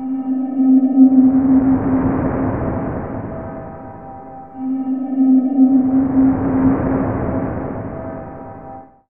Index of /90_sSampleCDs/Chillout (ambient1&2)/13 Mystery (atmo pads)
Amb1n2_w_pad_c.wav